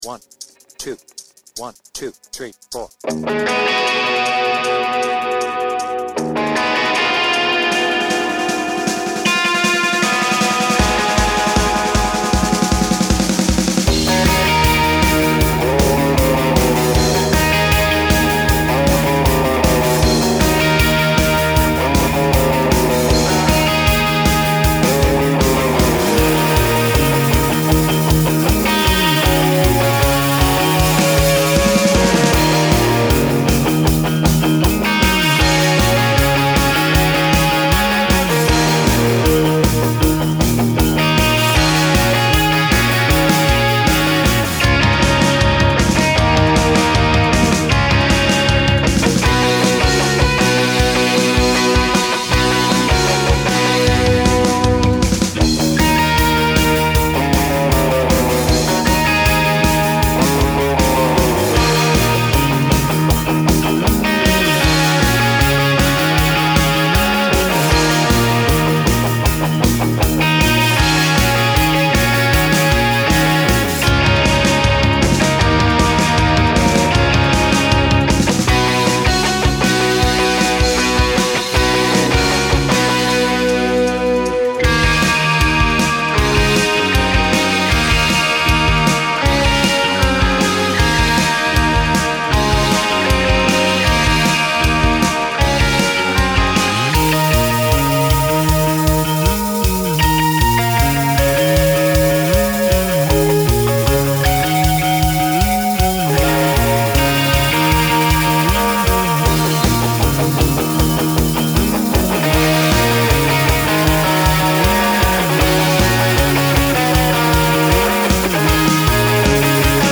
BPM : 156
Tuning : Eb
Without vocals
Based on the studio version